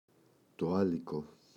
άλικο, το [Ꞌaliko]